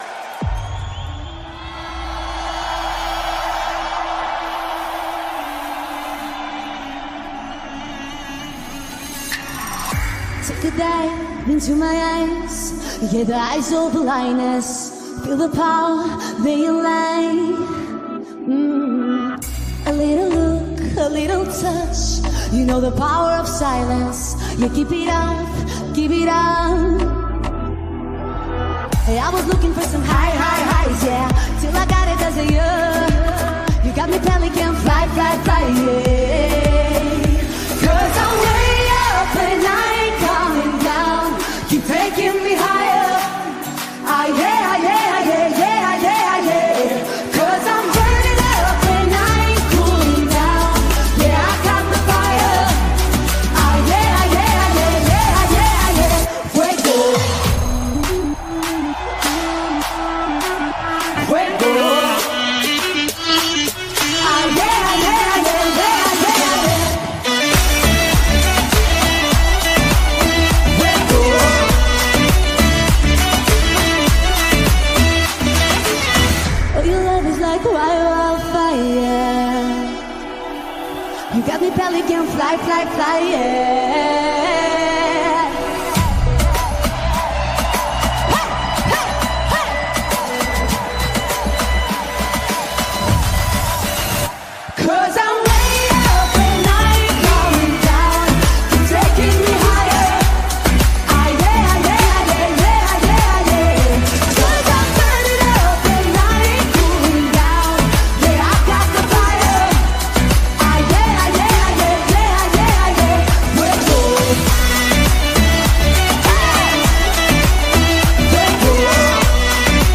BPM50-101
Audio QualityPerfect (High Quality)
Comments*The real minimum BPM of this song is 50.5